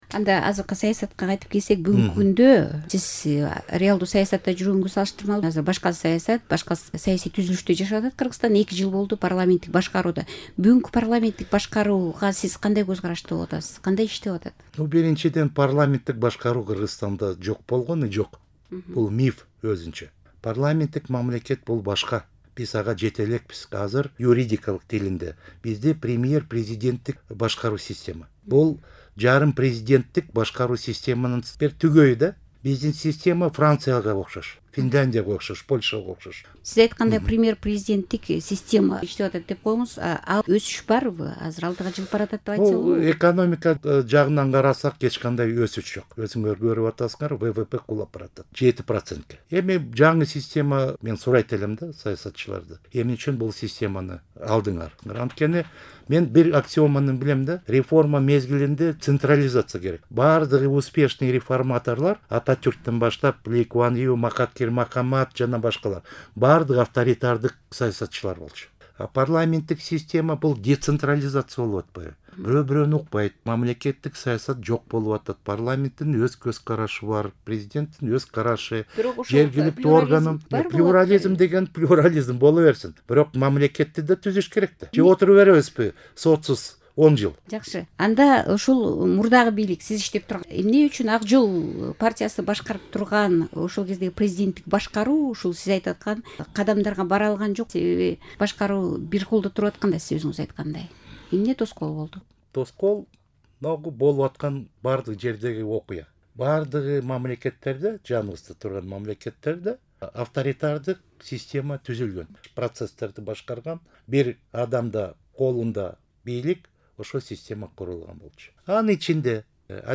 Зайнидин Курманов менен маек (2-бөлүк)